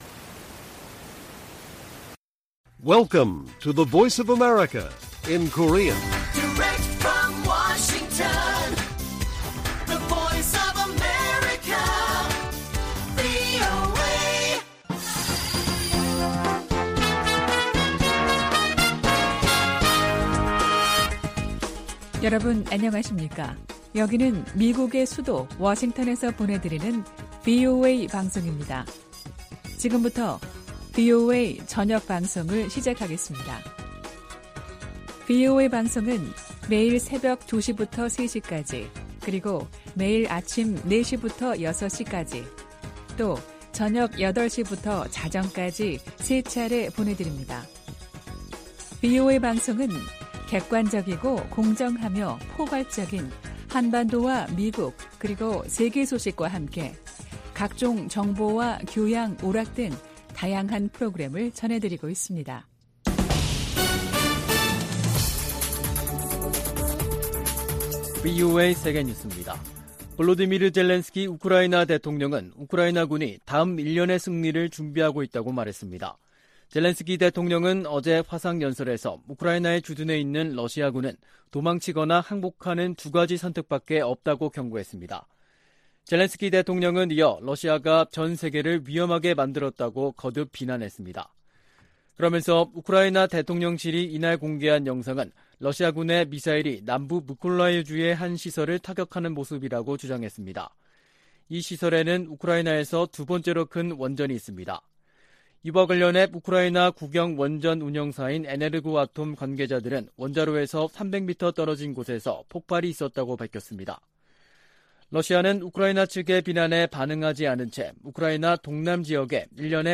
VOA 한국어 간판 뉴스 프로그램 '뉴스 투데이', 2022년 9월 20일 1부 방송입니다. 한국이 북한에 제안한 ‘담대한 구상’은 대화 초기부터 북한이 우려하는 체제안보와 정치, 군사적 문제를 논의할 수 있다는 취지라고 권영세 한국 통일부 장관이 말했습니다. 미 국방부가 향후 5년간 중국과 북한 등의 대량살상무기 위협 대응으로 억지, 예방, 압도적 우위의 중요성을 강조했습니다. 최근 중국 항구에 기항하는 북한 선박이 늘고 있는 것으로 나타났습니다.